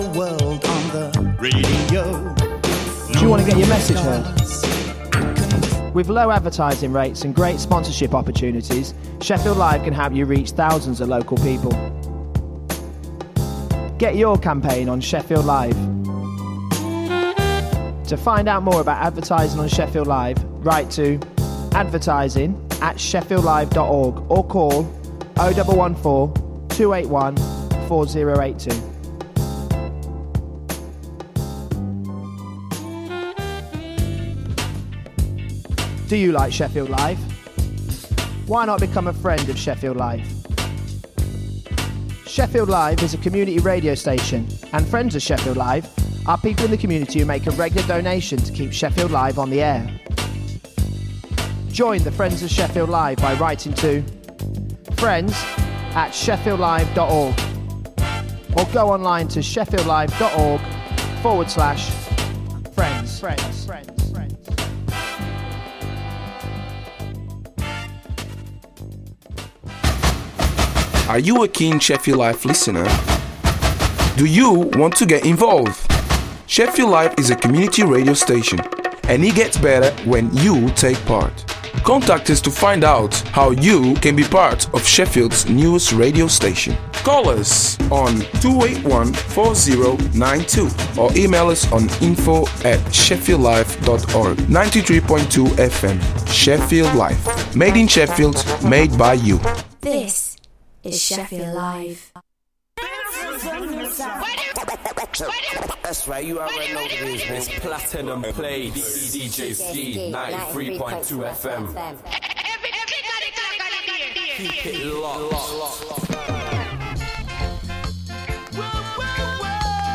Platinum Plates will re-introduce you to tracks and artist profiles/updates and will re-ignite the light for all the revival Reggae, Rockers, Conscious lyrics, Lovers, Ska and Version fanatics out there.